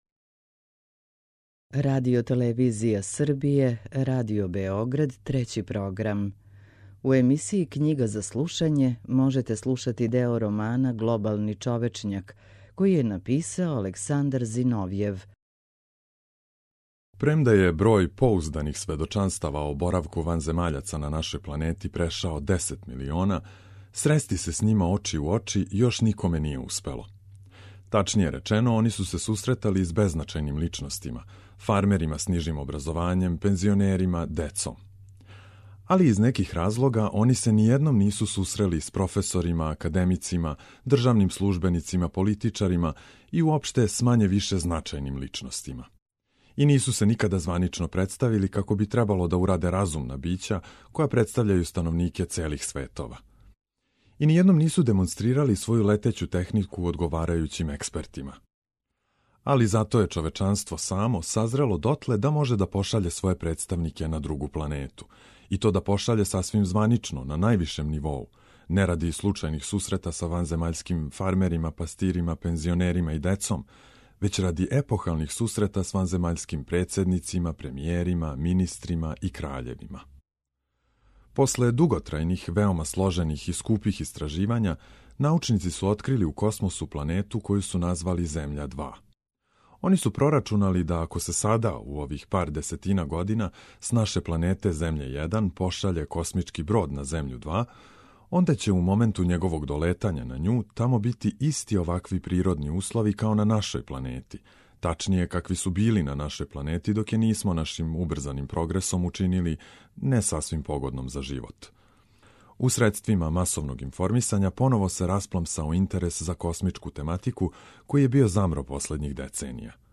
У емисији Књига за слушање можете пратити делове романа Александра Зиновјева „Глобални човечњак”.